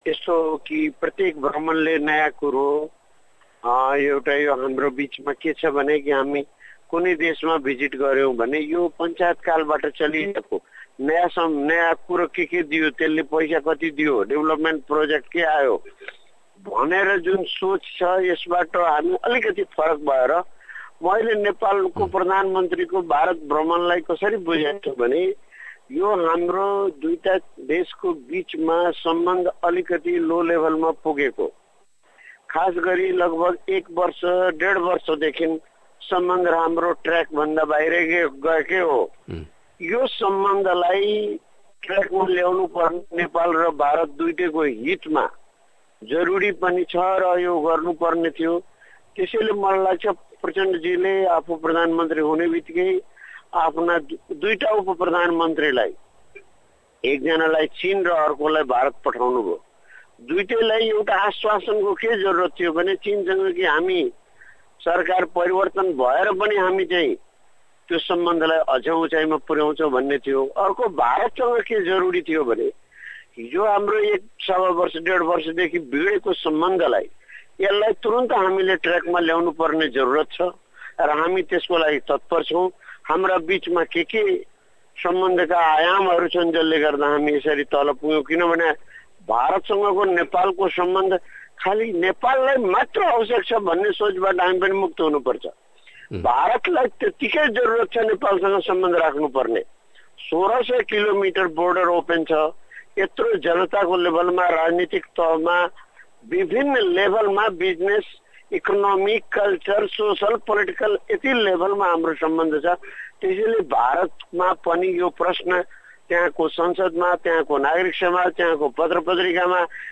डेनमार्कका लागि नेपालका पुर्व राजदूत र हाल त्रिभुवन विश्वविध्यालयमा राजनीति शास्त्रका प्राध्यापक रहनुभएका विजय कान्त कर्ण यसो भन्नुहुन्छ।